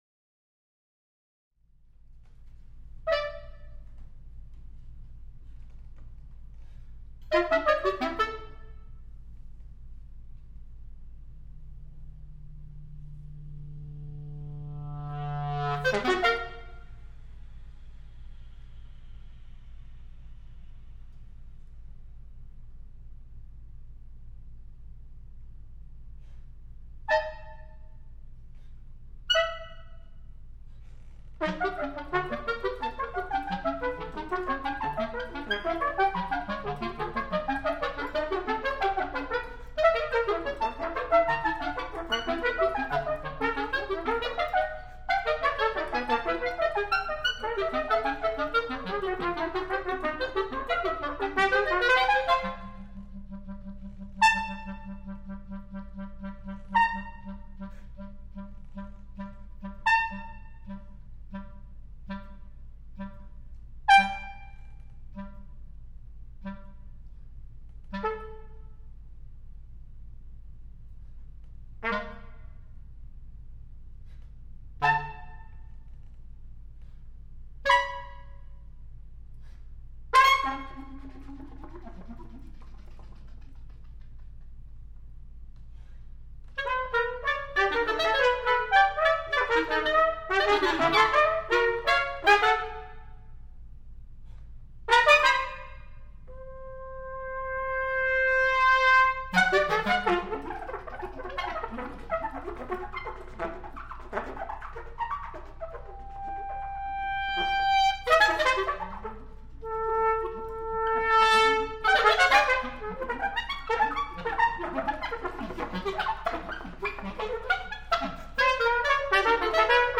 Three Sketchbook Duos for trumpet and clarinet.
The score indicates gestural shapes in varying degrees of detail, some fully notated, some indications with which to improvise.